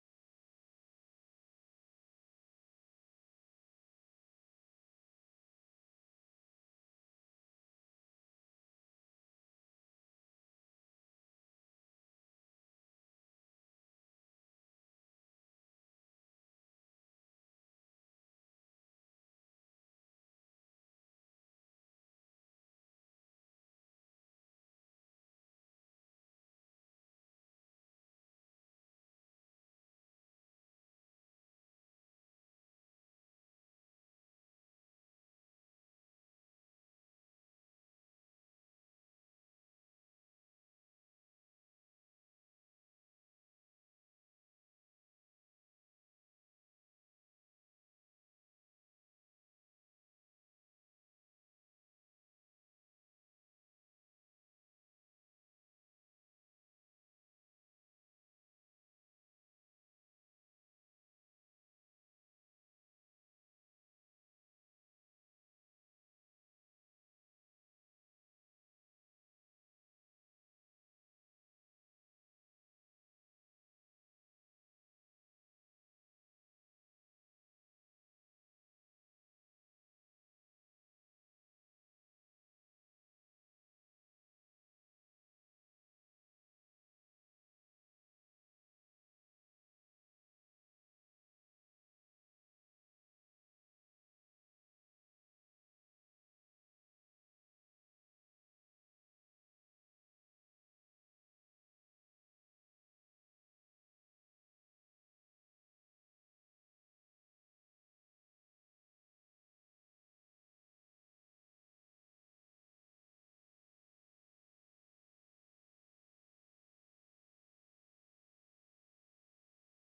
Аудиокнига Смертельные прятки | Библиотека аудиокниг